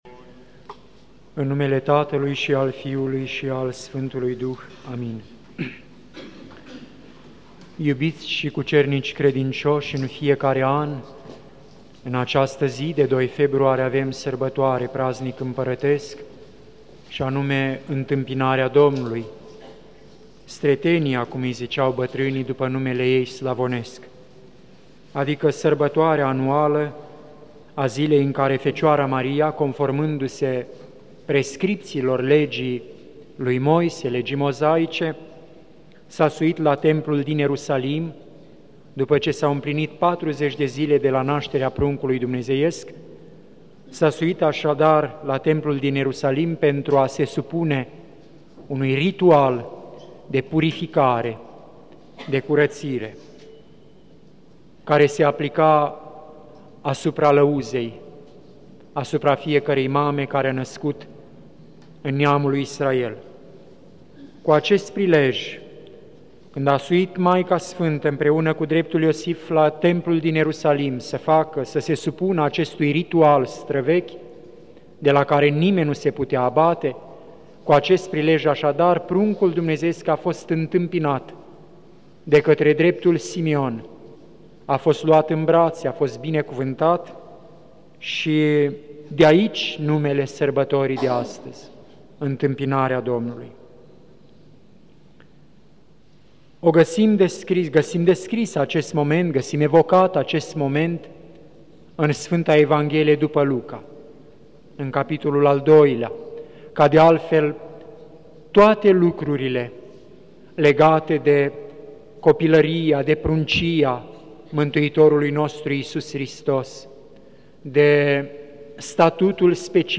Predică la sărbătoarea Întâmpinării Domnului
Cuvinte de învățătură Predică la sărbătoarea Întâmpinării Domnului